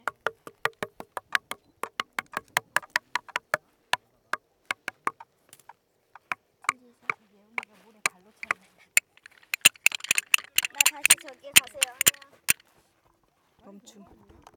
두드리는04.ogg